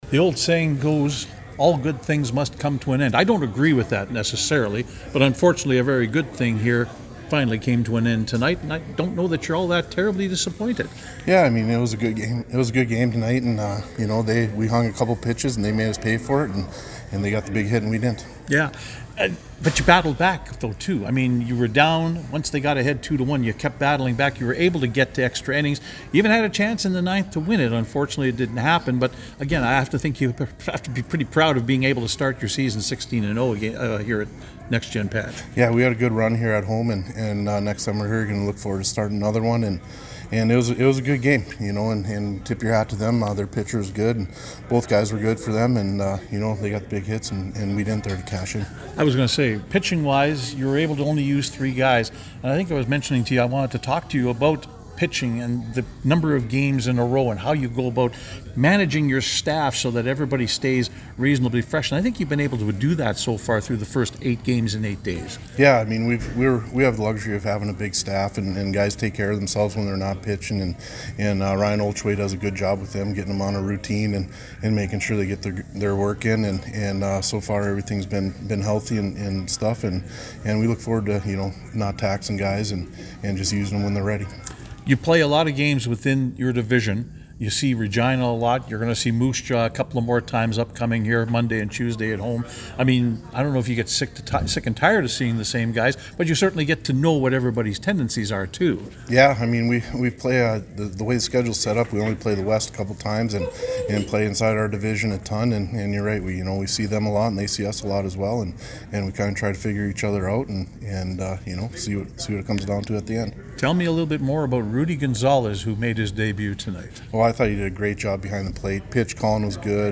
post-game conversation